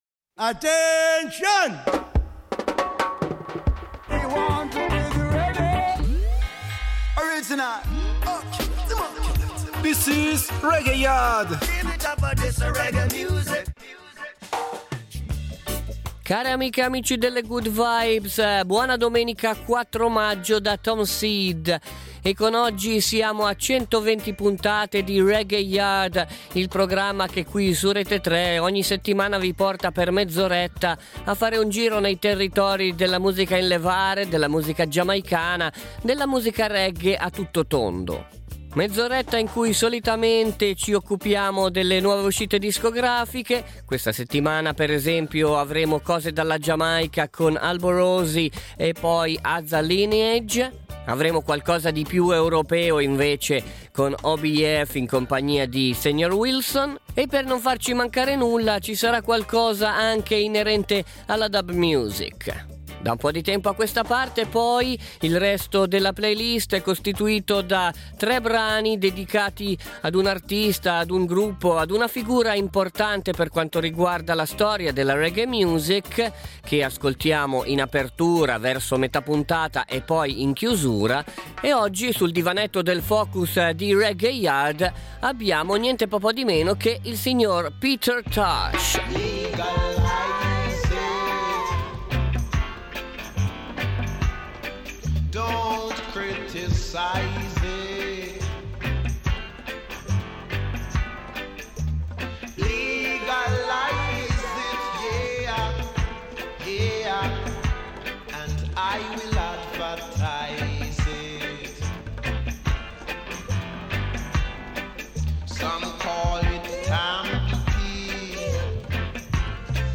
REGGAE / WORLD